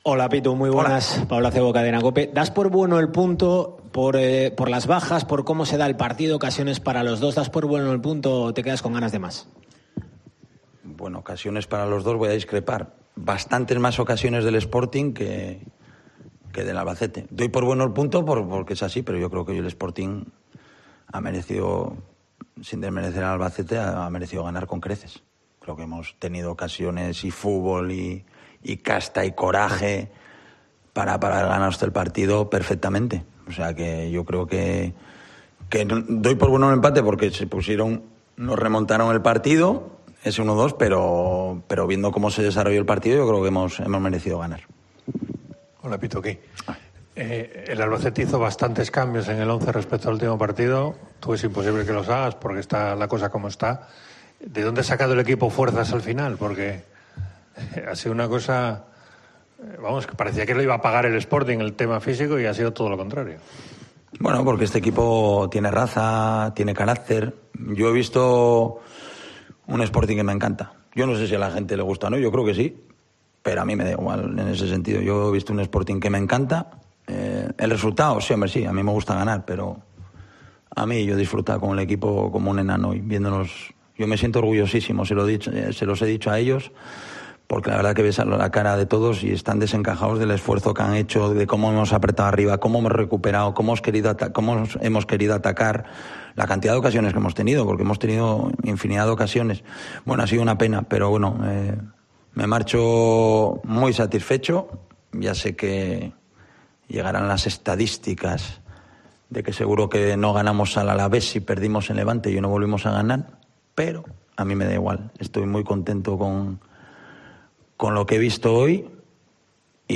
Rueda de prensa Abelardo (post Albacete)